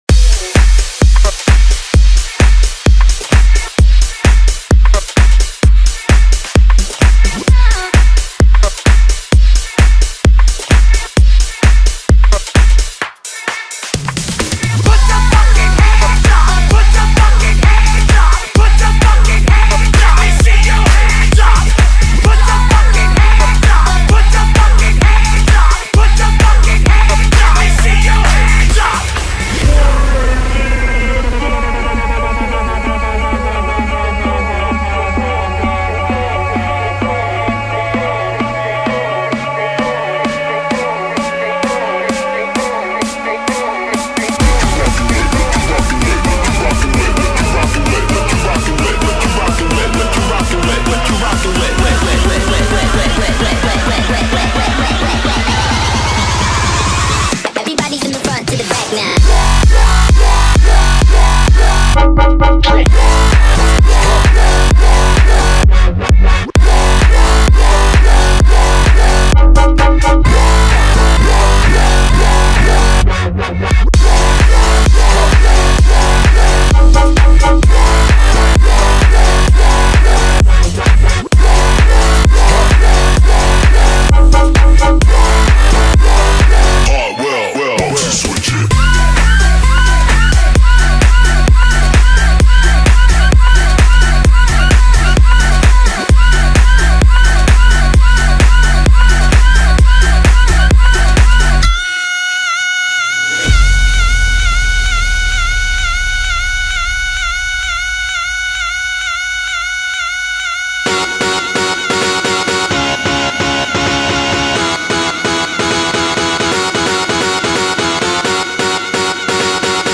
100 Bpm Genre: 2000's Version: Clean BPM: 100 Time